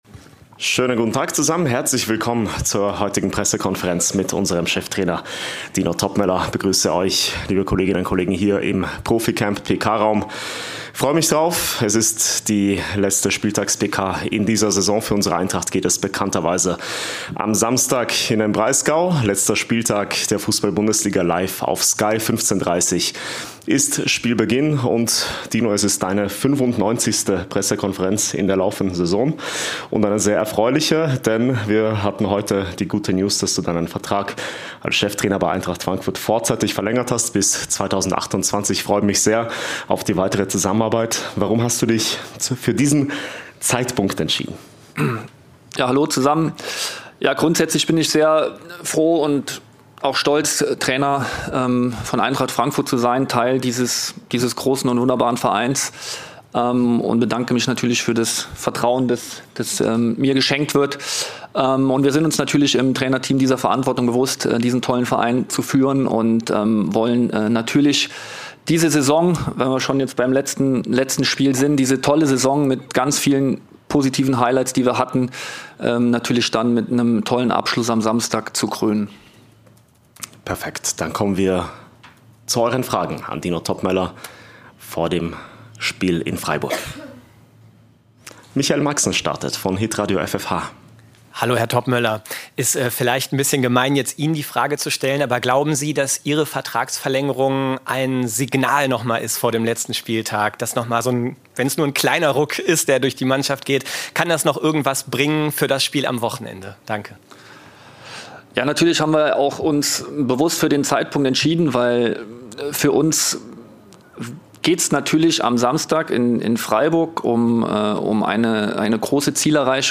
Die Pressekonferenz mit Cheftrainer Dino Toppmöller vor dem letzten Bundesligaspiel der Saison beim SC Freiburg.